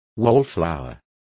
Complete with pronunciation of the translation of wallflower.